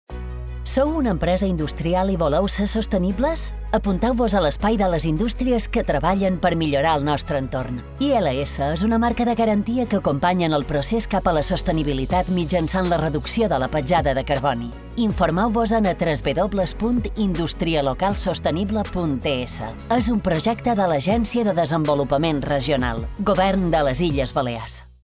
Cuñas
Arxiu Multimedia Cuña Industria Local Sostenible (.mp3)